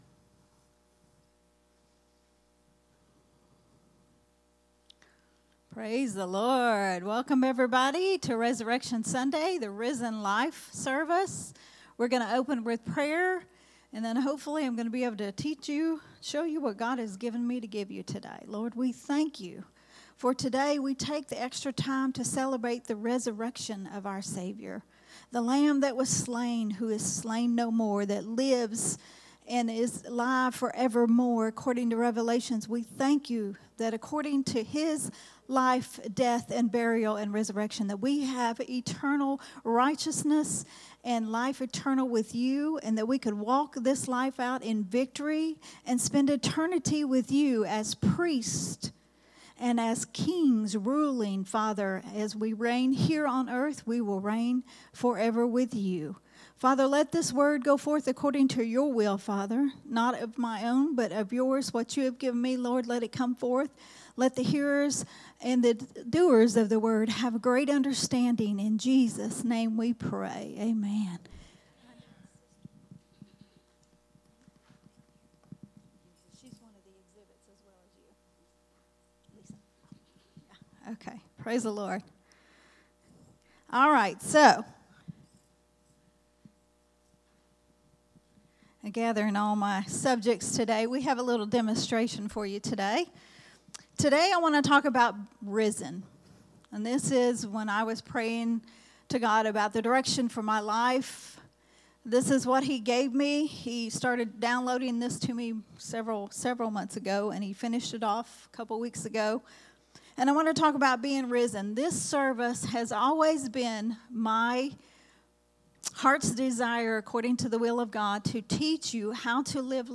a Sunday Morning Risen Life teaching